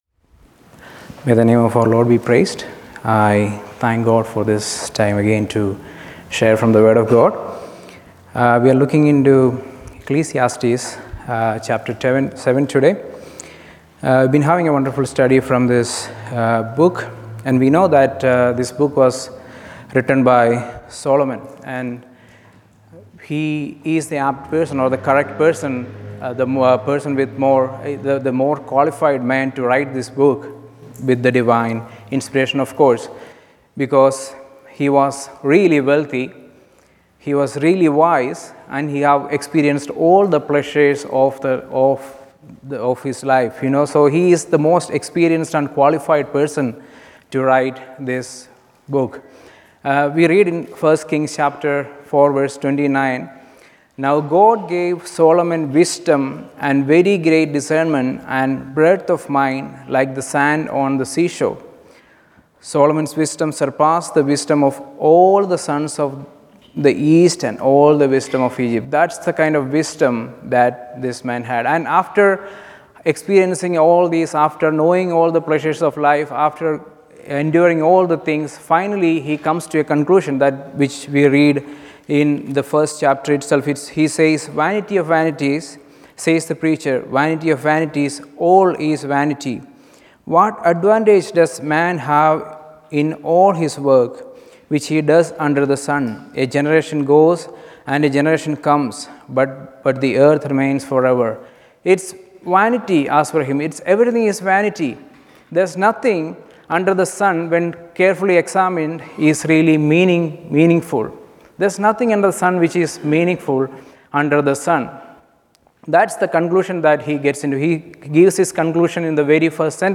Ecclesiastes Passage: Ecclesiastes 7:1-14 Service Type: Evening Service Topics